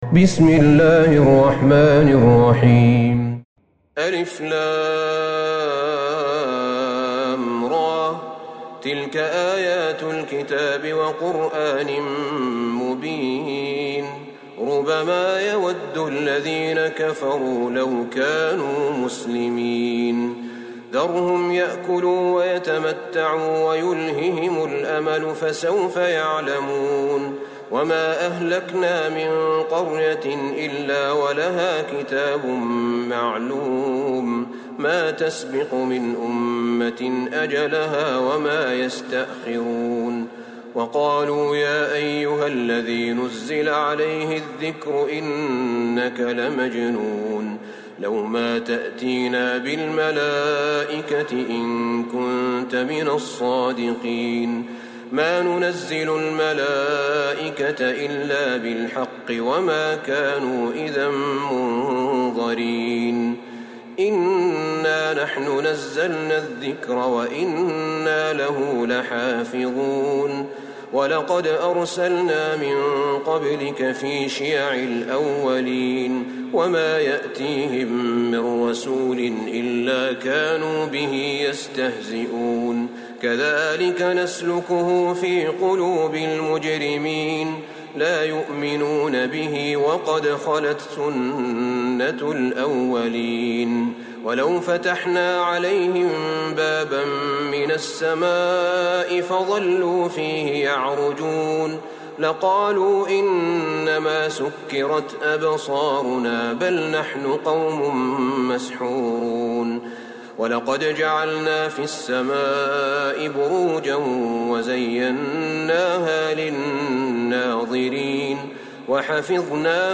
سورة الحجر Surat Al-Hijr > مصحف الشيخ أحمد بن طالب بن حميد من الحرم النبوي > المصحف - تلاوات الحرمين